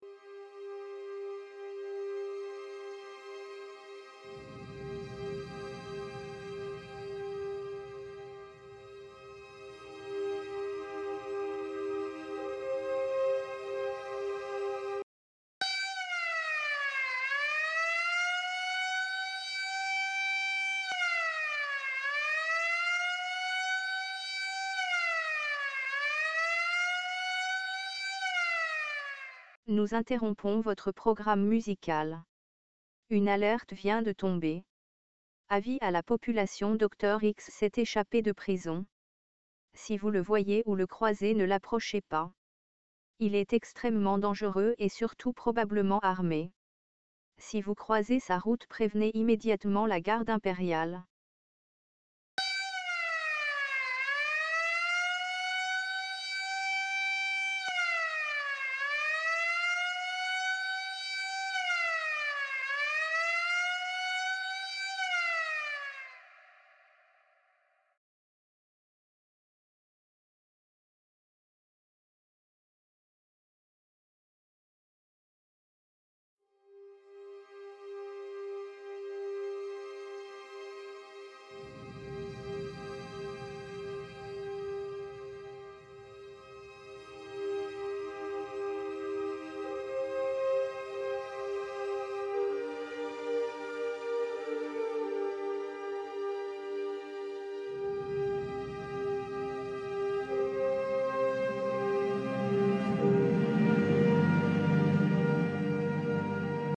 Tu profites de ce moment de calme pour écouter tranquillement la radio...
Et là un flash spécial attire ton oreille.